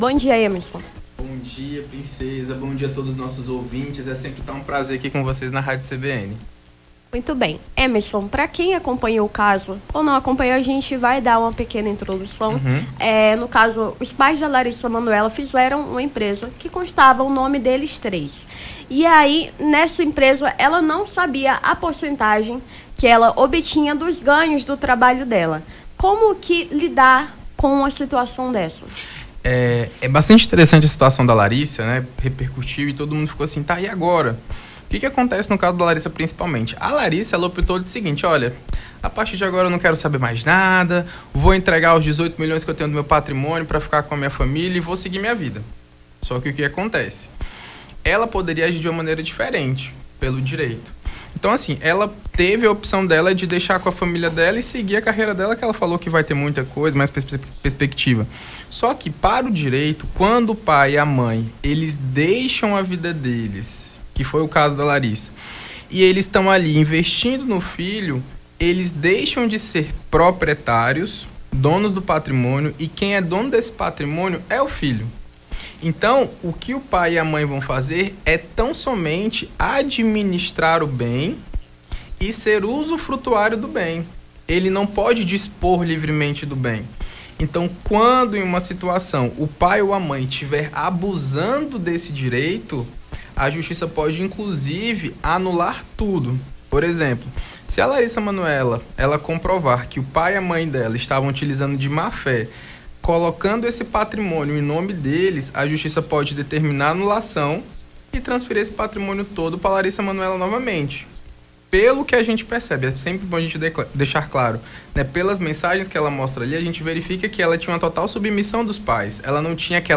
Nome do Artista - CENSURA - ENTREVISTA (SEUS DIREITOS) 18-08-23.mp3